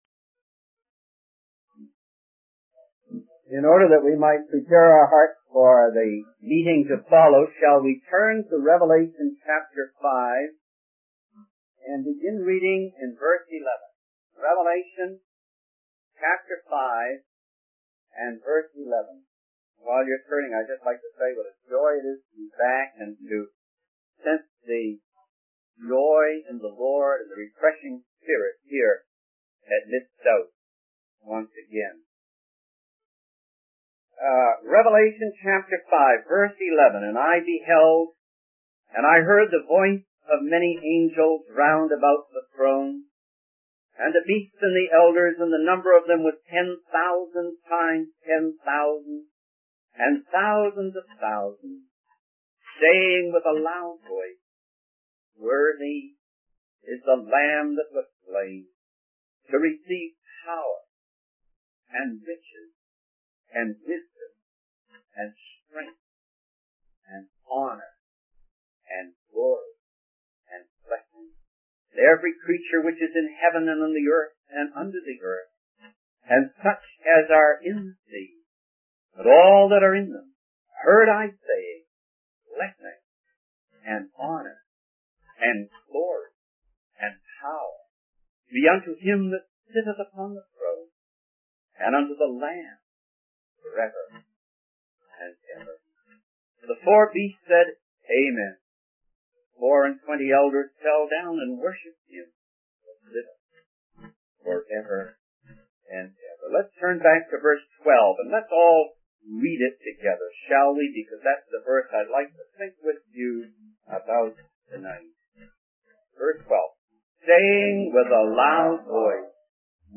In this sermon, the preacher emphasizes the importance of worshiping and praising the Lord with all our strength and passion. He compares our enthusiasm for worldly things like sports and hobbies to our lack of enthusiasm in pouring blessings upon the worthy Lord Jesus.